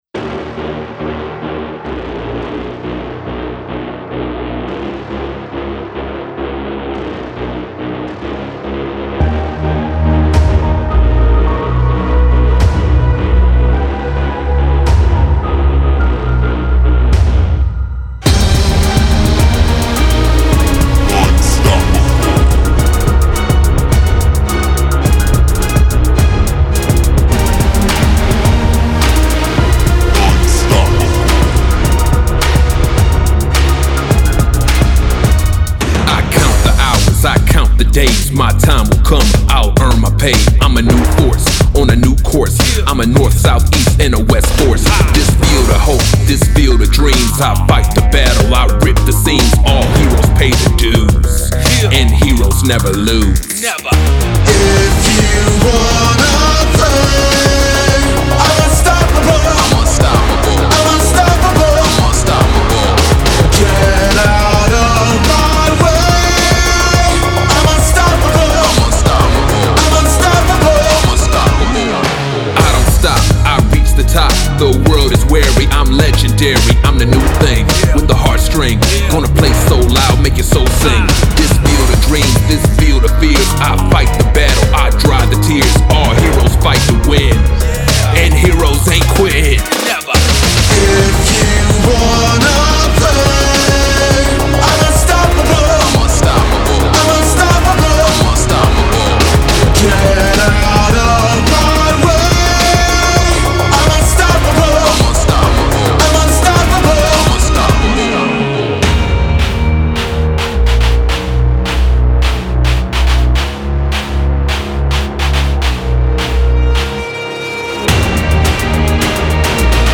Hip Hop/Trap Song with Vocals